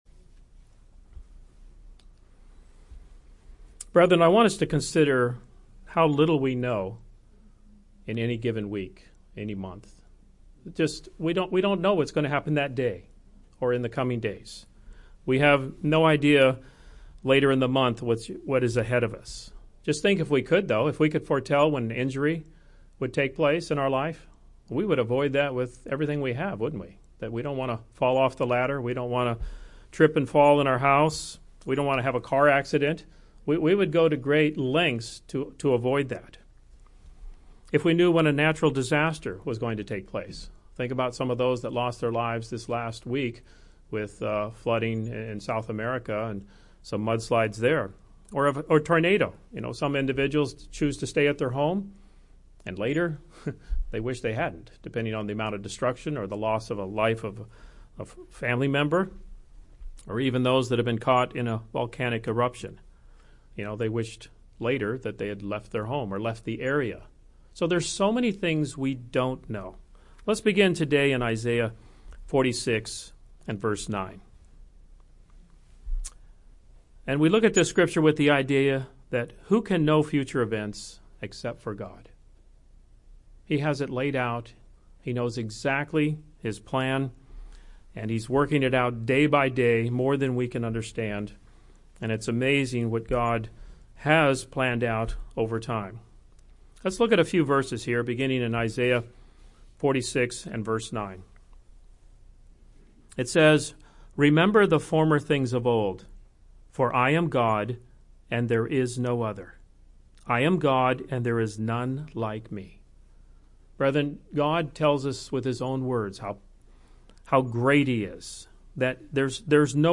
It’s easy to claim that we believe in God, but do we reflect this by the way we live our life? This sermon explores the faith of Abraham, as a great example of a man who put his whole heart into obeying God. As Christians, we’re also asked to have living faith – how are we doing?